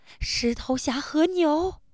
fear